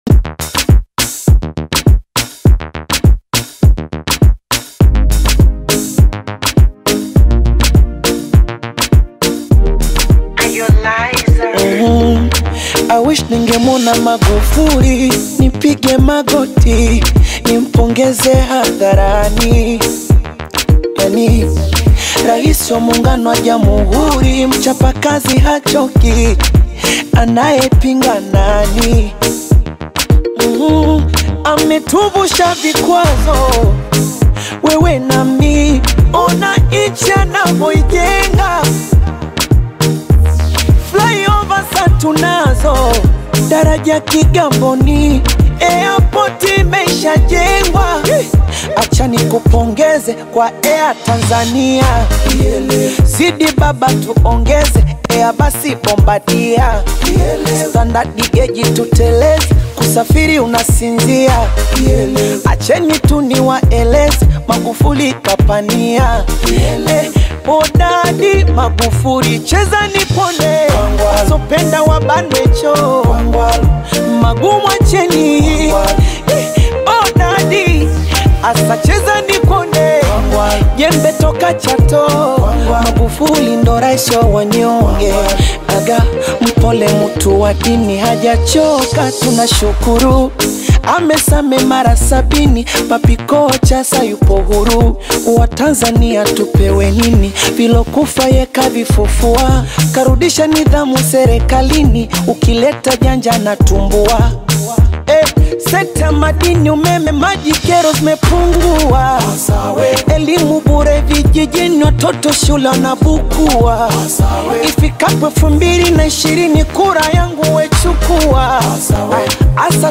bongo